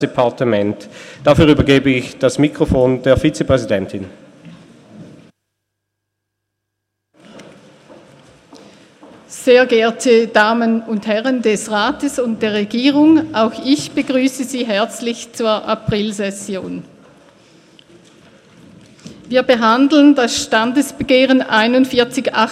Session des Kantonsrates vom 23. und 24. April 2018